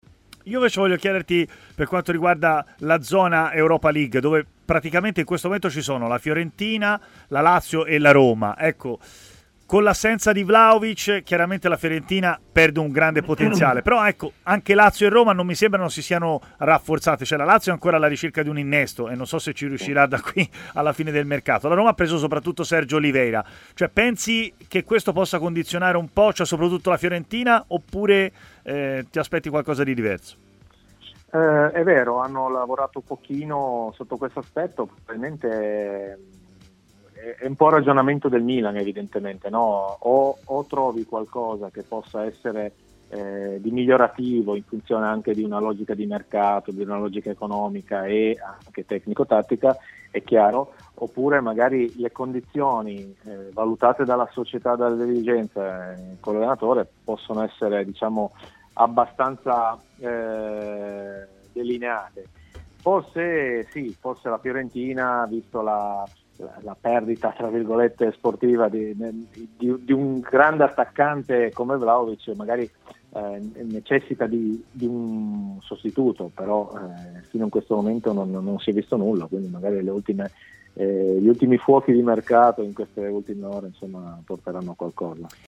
L'ex difensore Valerio Bertotto, oggi allenatore, ha parlato a Stadio Aperto, trasmissione di TMW Radio, delle possibilità della Fiorentina di arrivare in zona Europa dopo la cessione di Dusan Vlahovic alla Juventus.